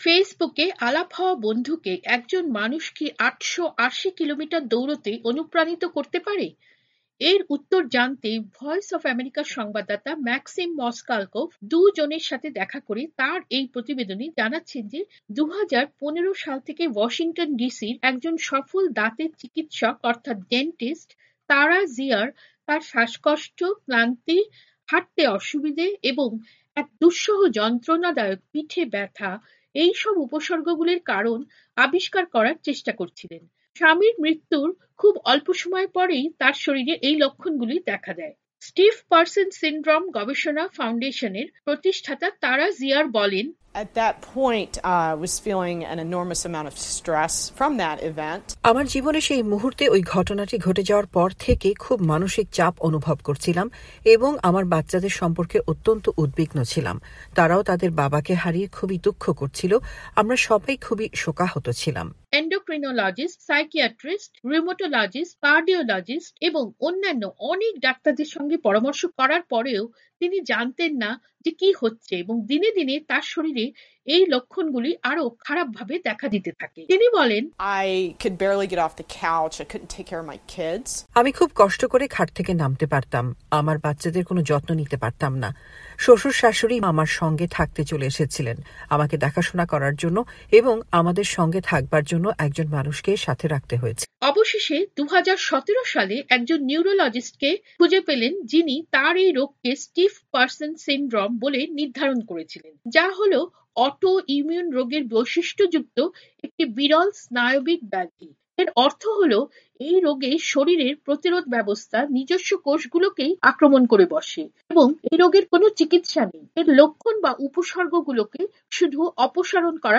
প্রতিবেদনটি পড়ে শোনাচ্ছেন